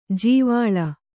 ♪ jīvāḷa